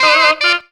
NICE RIFF.wav